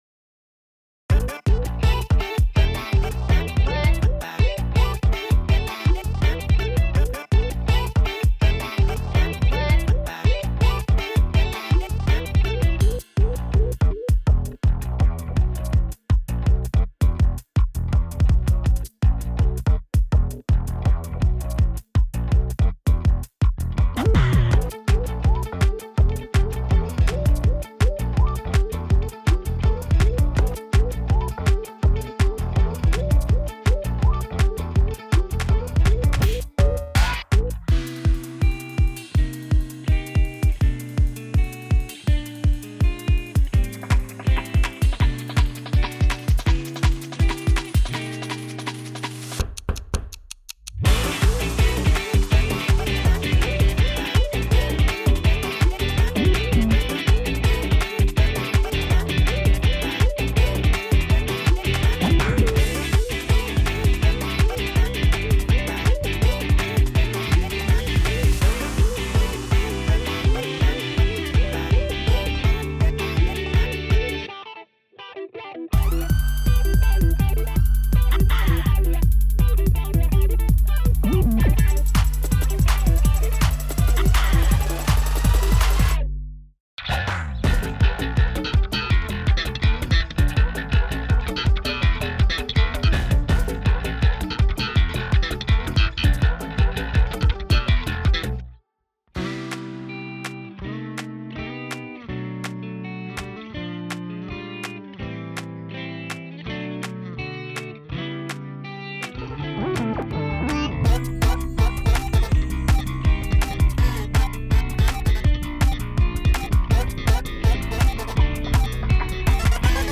Inst : MP3Lyric : TXT